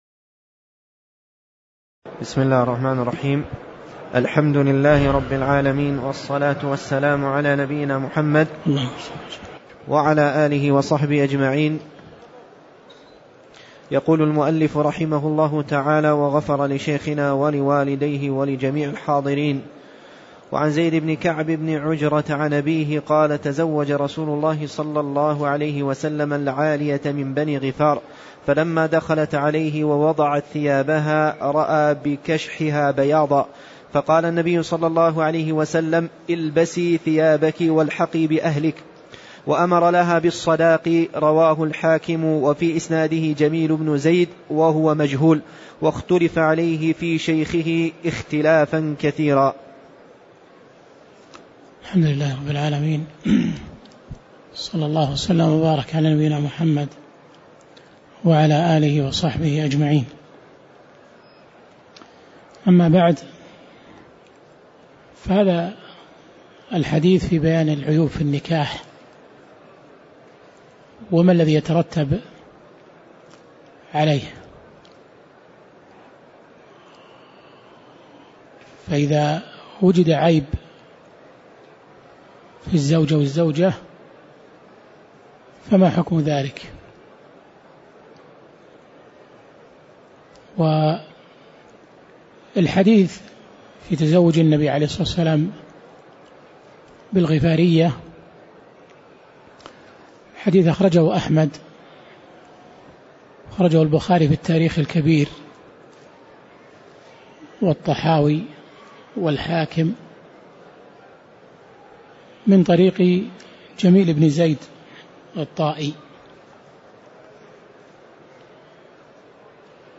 تاريخ النشر ٣٠ ربيع الثاني ١٤٣٧ هـ المكان: المسجد النبوي الشيخ